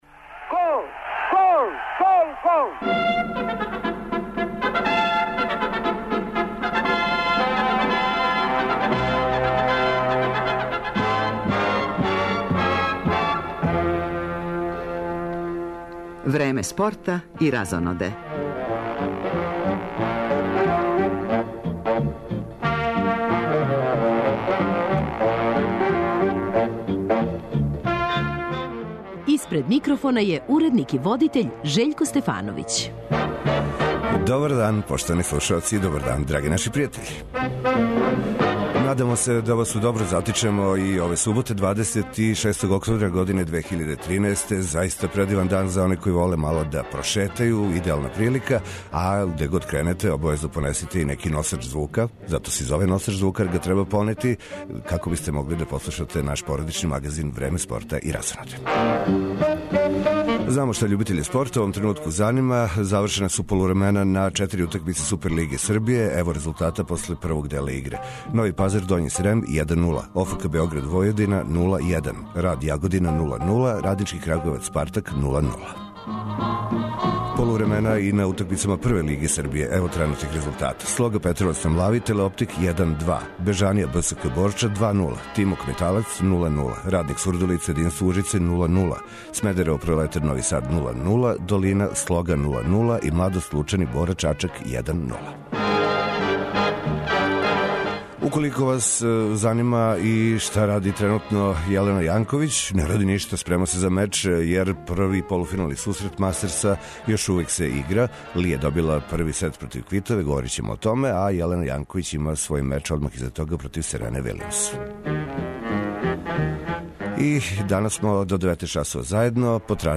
У оквиру емисије пратимо дешавања на утакмици Лиге шампиона у ватерполу, између крагујевачког Радничког и Ваљугменија из Грчке, као и на фудбалским утакмицама наших и европских фудбалских шампионата.